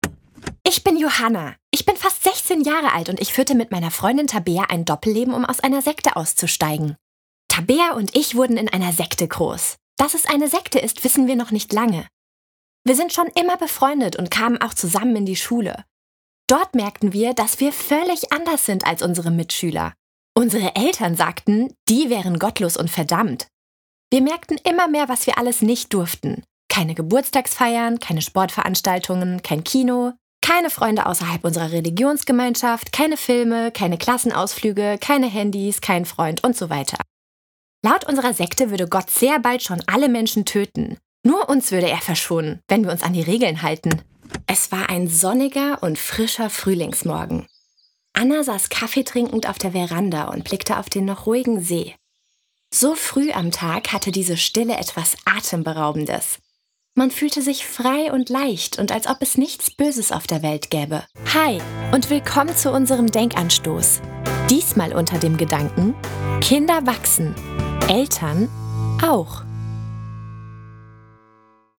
Junge weibliche Sprecher Stimmen
Eine jugendliche, hohe, frische Frauenstimme für junge Zielgruppen